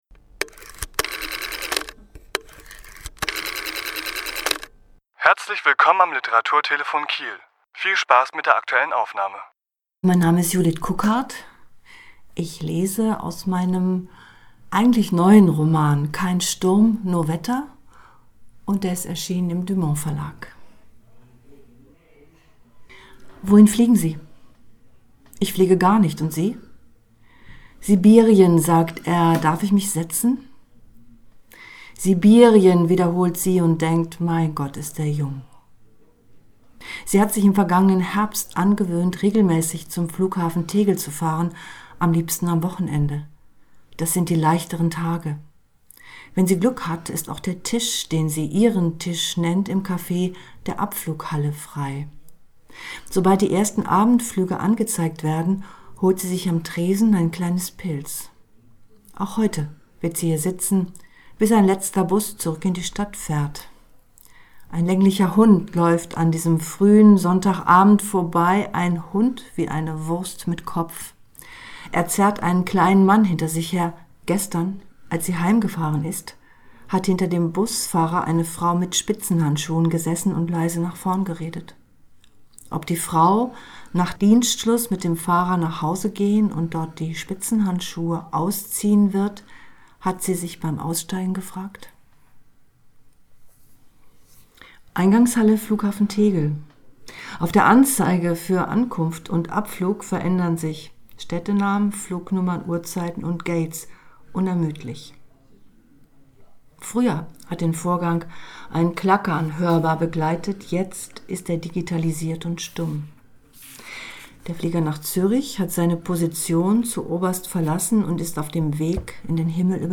Autor*innen lesen aus ihren Werken
Die Aufnahme entstand im Rahmen einer Lesung im Literaturhaus S.-H. am 20.2.2020.